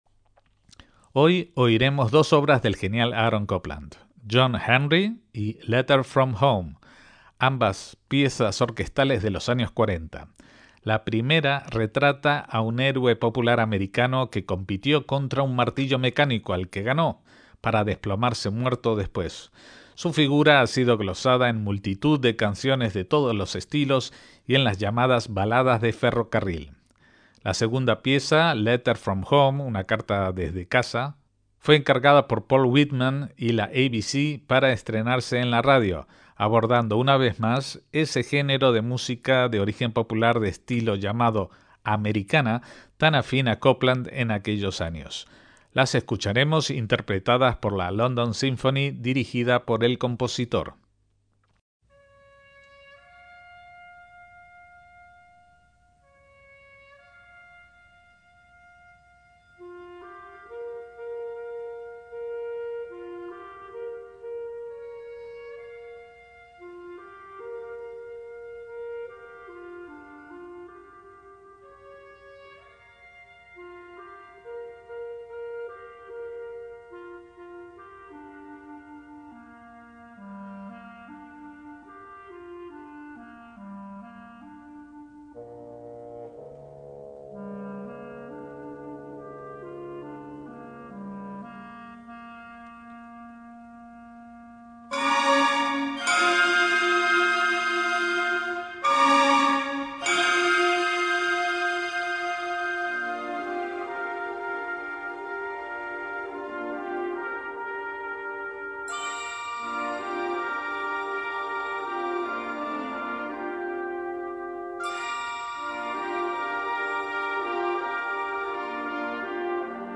obra orquestal de tintes patrióticos